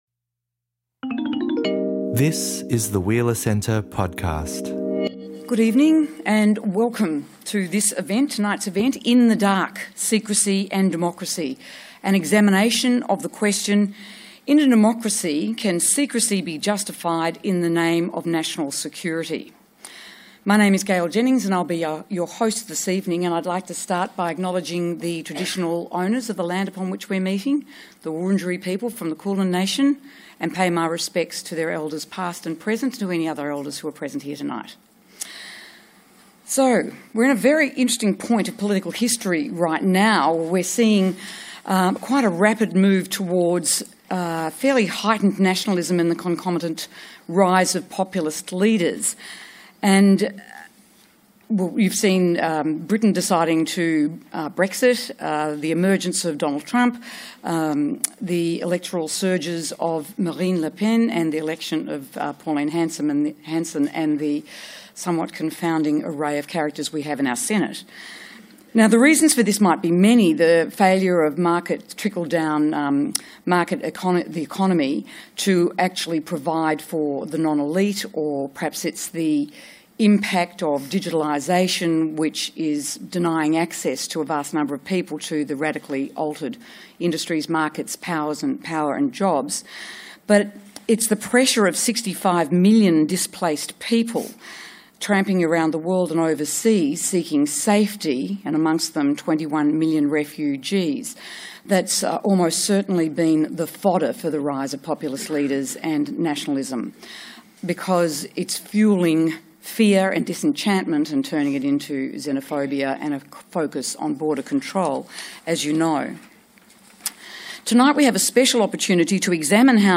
Can secrecy be justified in the name of national security? At this forum event, three speakers respond specifically to this provocation.